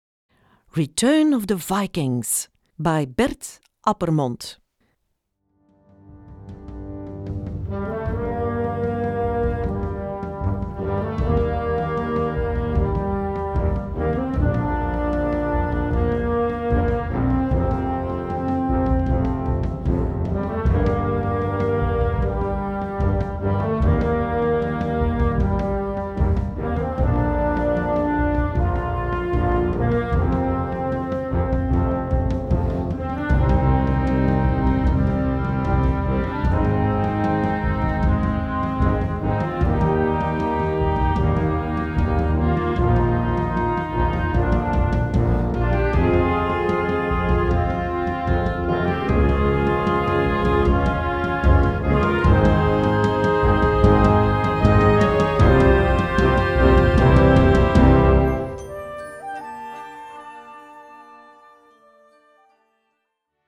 Répertoire pour Harmonie/fanfare - Concert Band Ou Harmonie